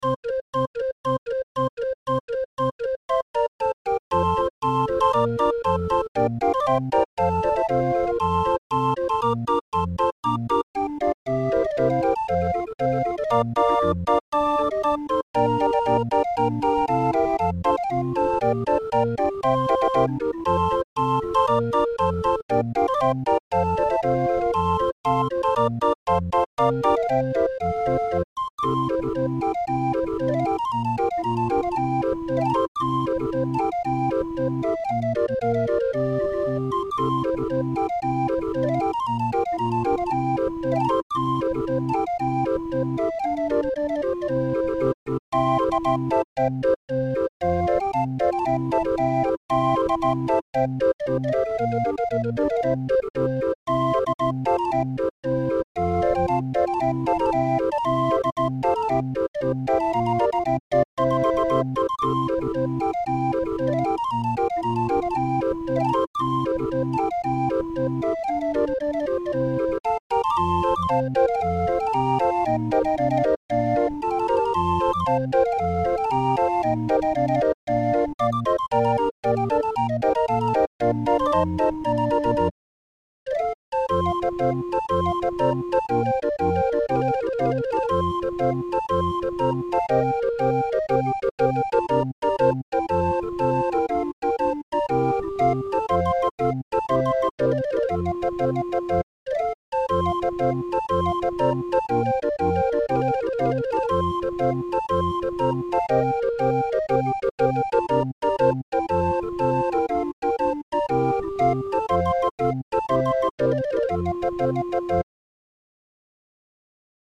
20-er draaiorgel midi-file